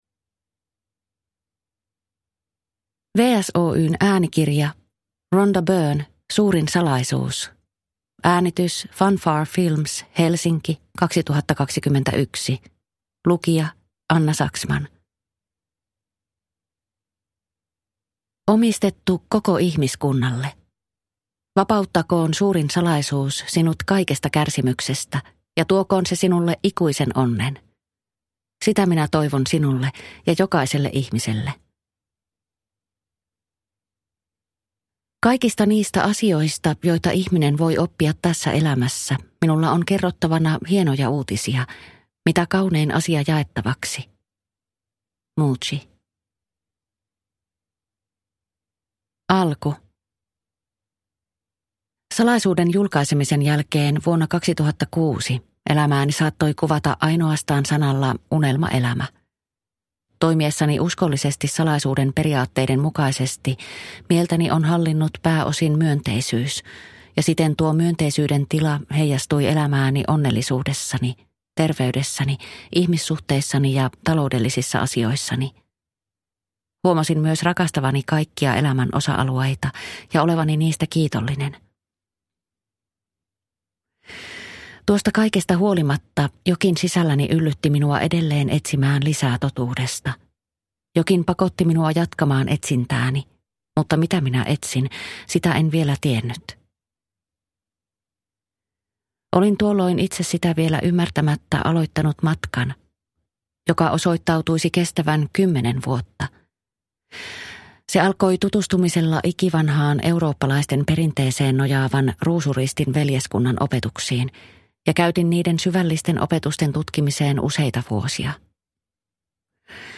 The Secret. Suurin salaisuus – Ljudbok